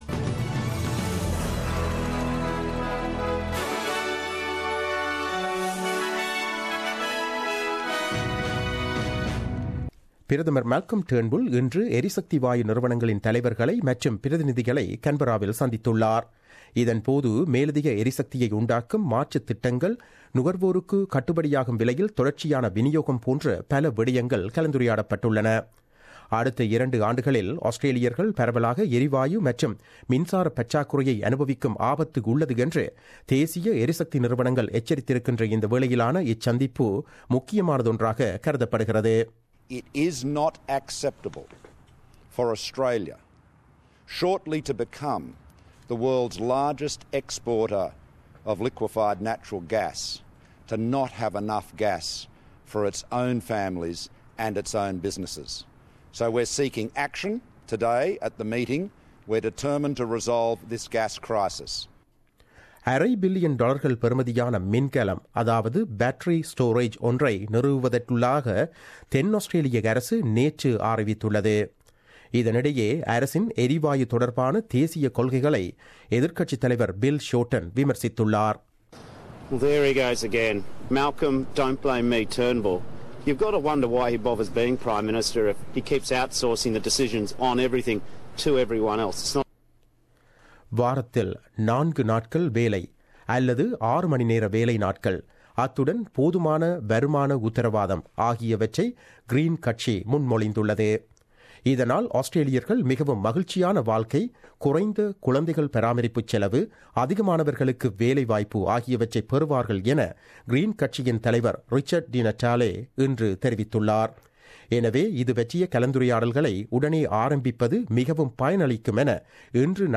The news bulletin aired on 15 March 2017 at 8pm.